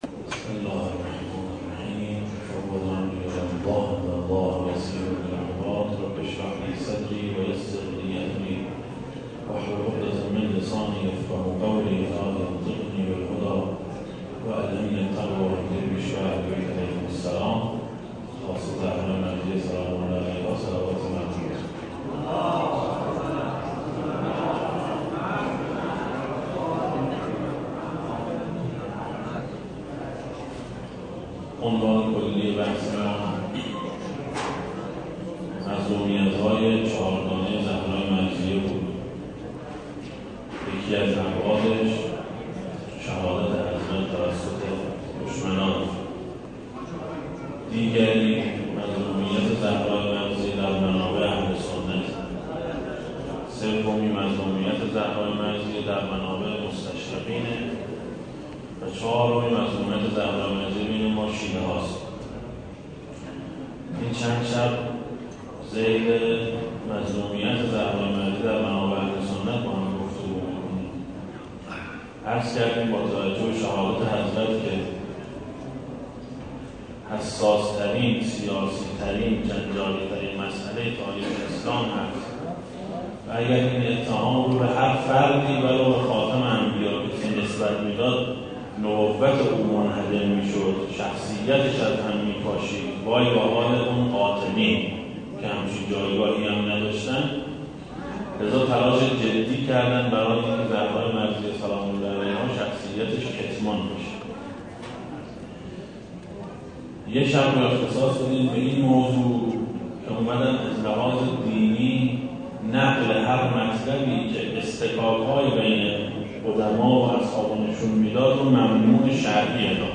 دسته: ابعاد مظلومیت حضرت زهرا سلام الله علیها, حضرت فاطمه سلام الله علیها, سخنرانی ها